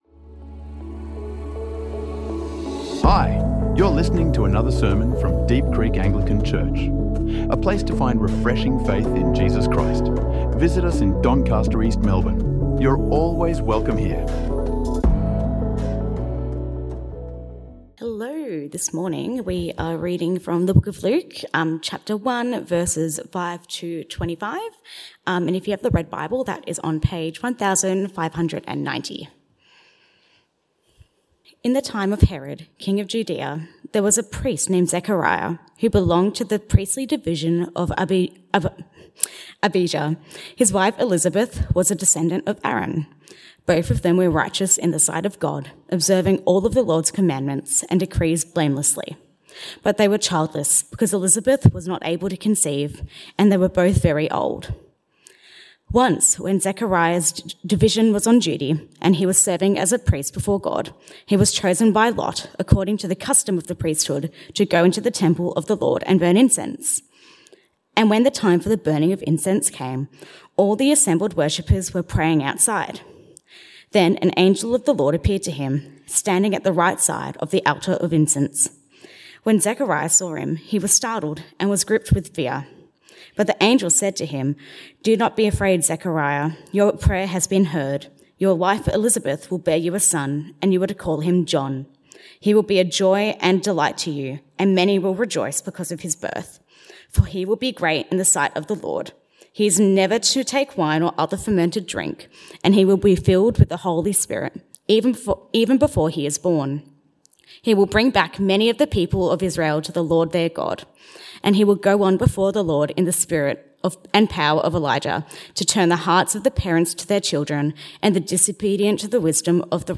Waiting for Peace | Sermons